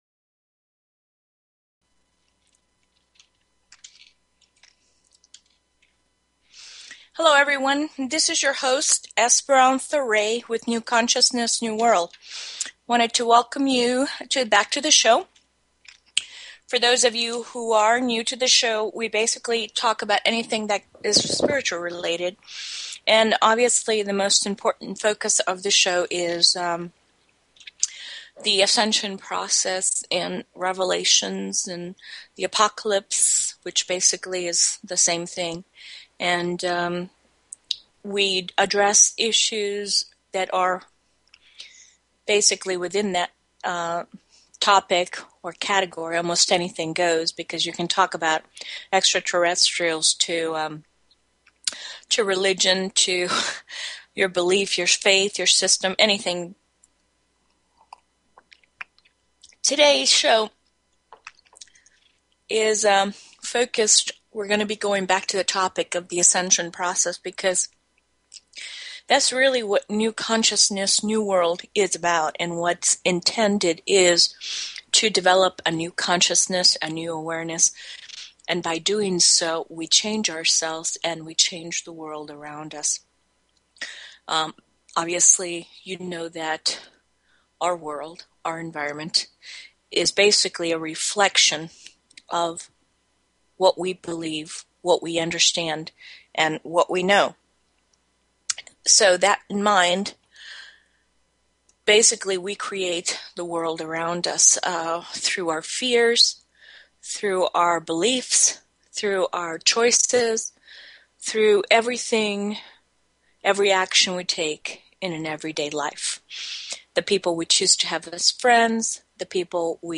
Talk Show Episode, Audio Podcast, New_Consciousness_New_World and Courtesy of BBS Radio on , show guests , about , categorized as